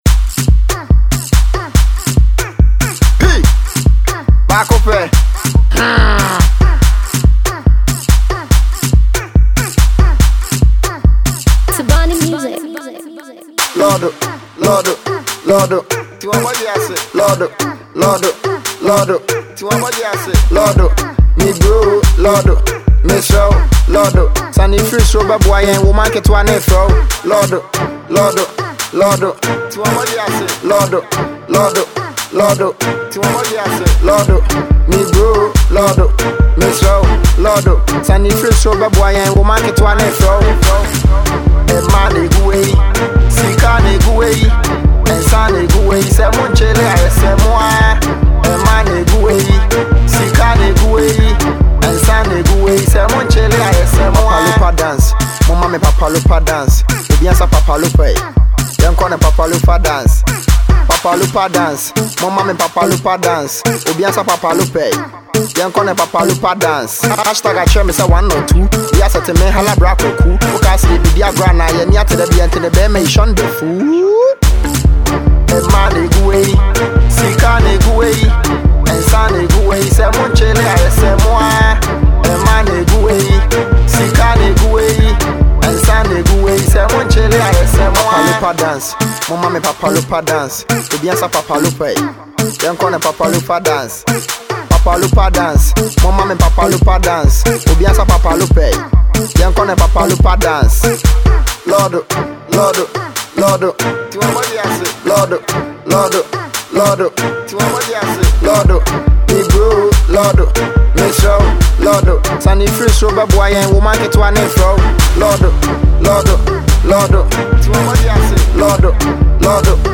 rappers